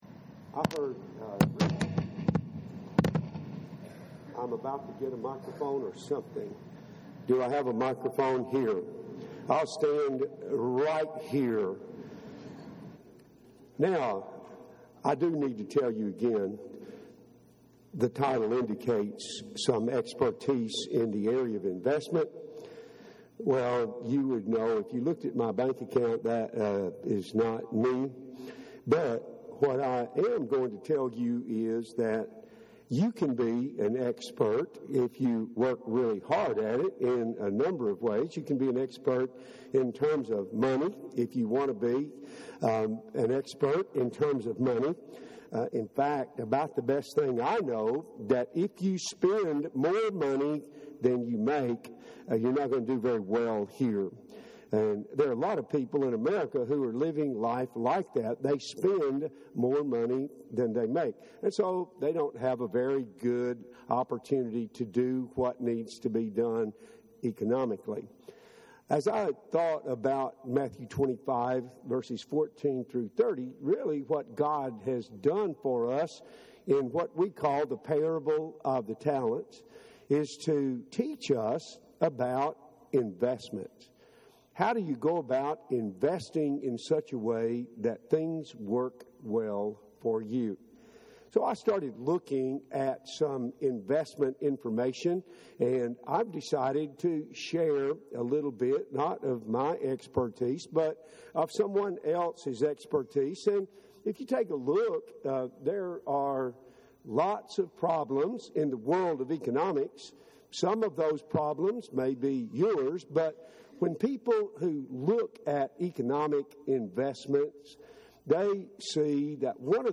Check Your Investment Portfolio – Henderson, TN Church of Christ